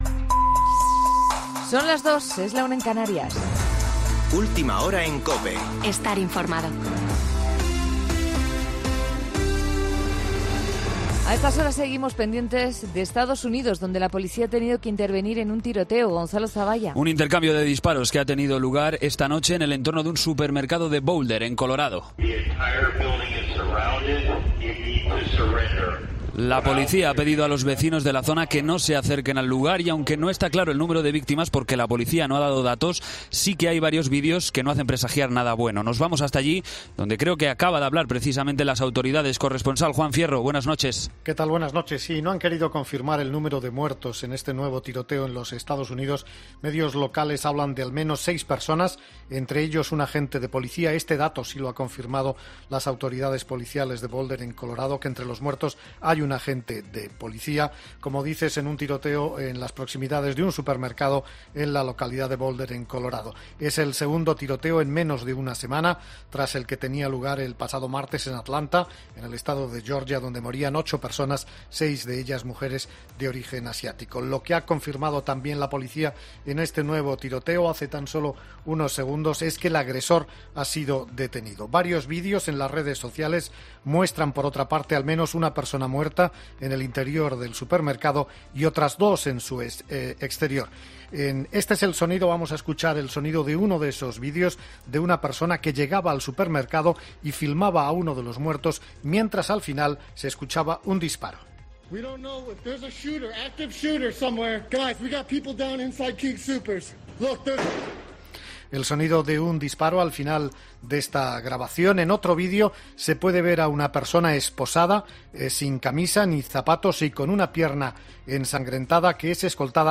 Boletín de noticias COPE del 23 de marzo de 2021 a las 02.00 horas